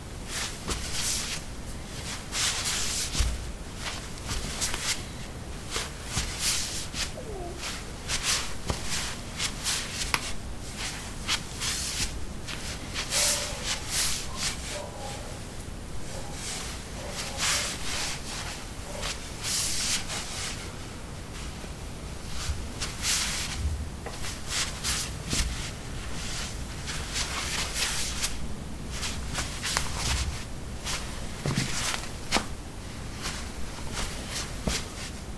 脚踏实地的瓷砖 " 陶瓷03C拖鞋运行
描述：在瓷砖上运行：拖鞋。在房子的浴室里用ZOOM H2记录，用Audacity标准化。